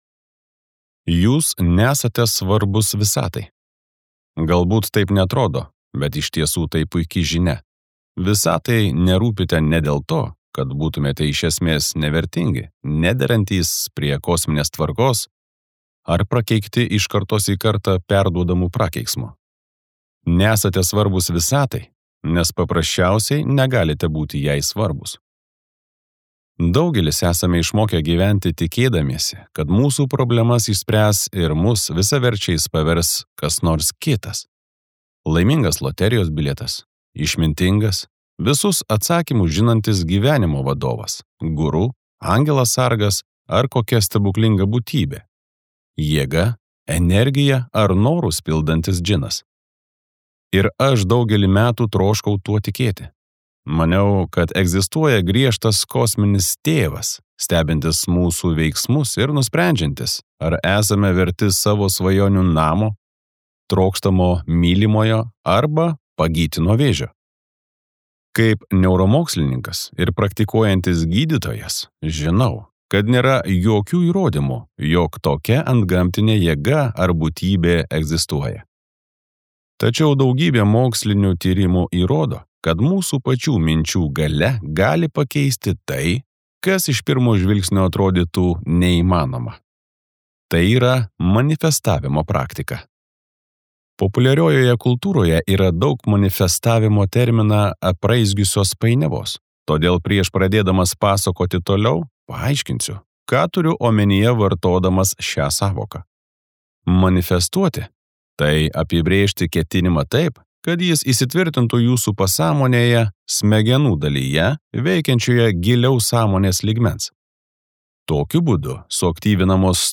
Skaityti ištrauką play 00:00 Share on Facebook Share on Twitter Share on Pinterest Audio Proto magija.